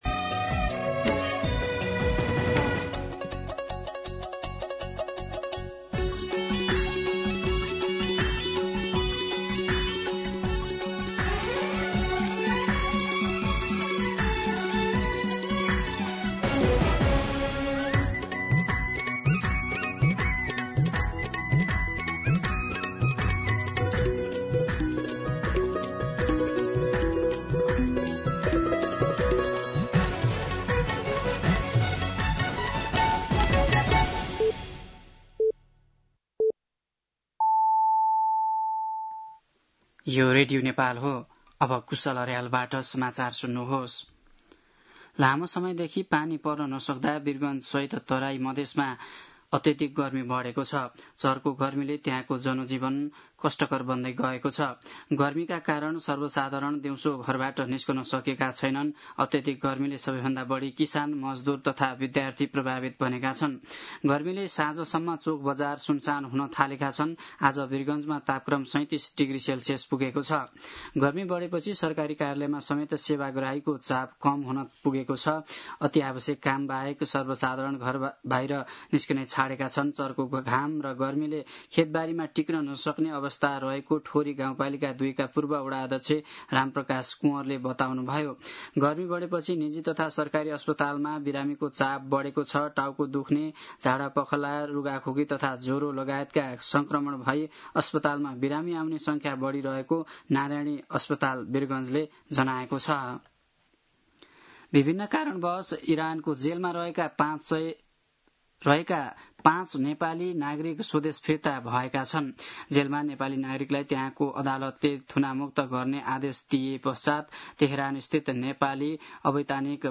दिउँसो ४ बजेको नेपाली समाचार : १० साउन , २०८२
4-pm-Nepali-News-3.mp3